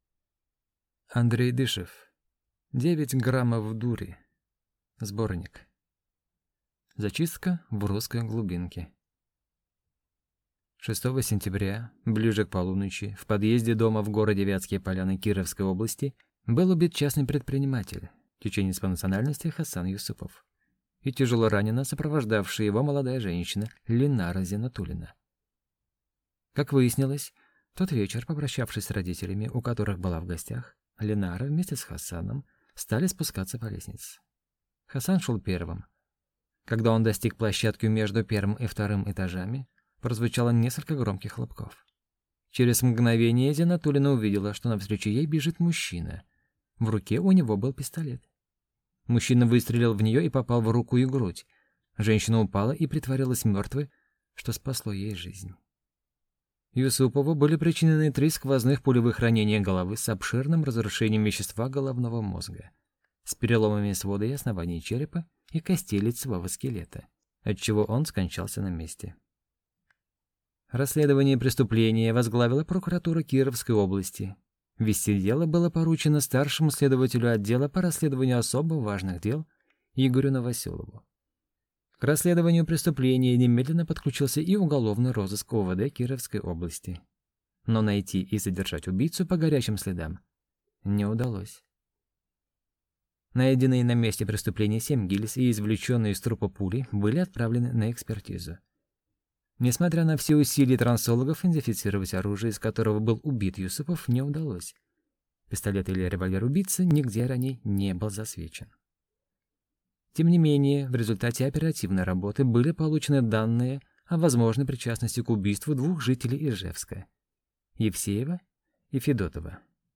Аудиокнига Девять граммов дури | Библиотека аудиокниг